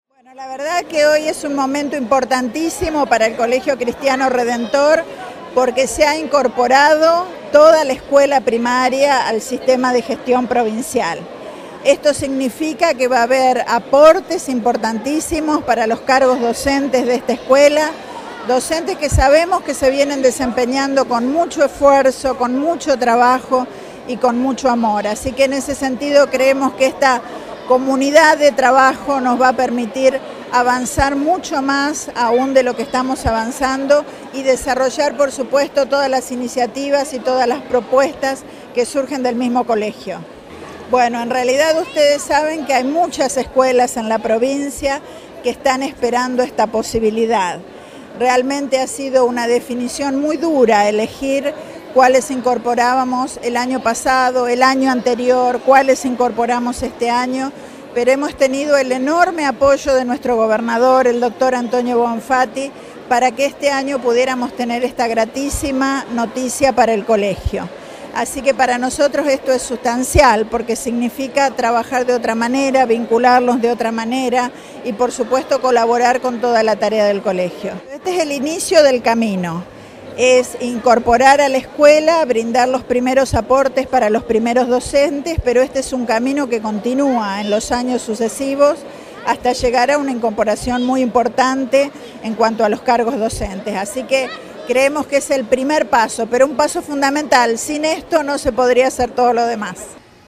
Declaraciones a la prensa de la ministra Claudia Balagué